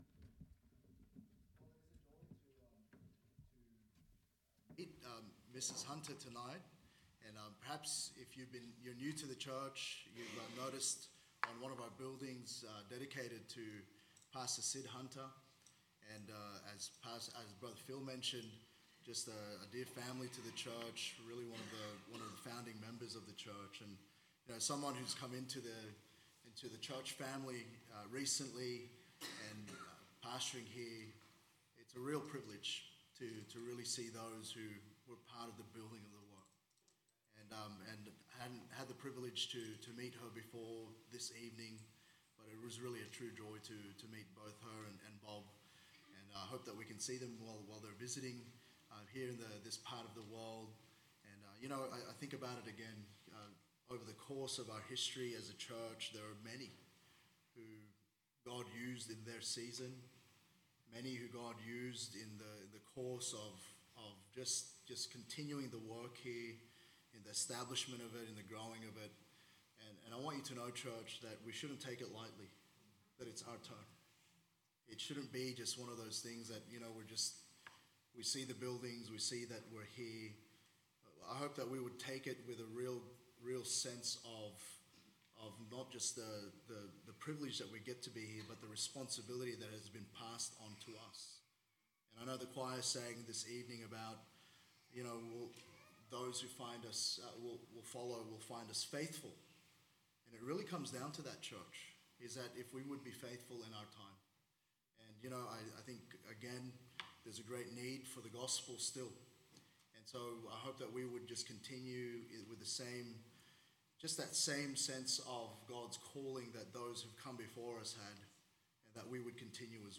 Sermons | Good Shepherd Baptist Church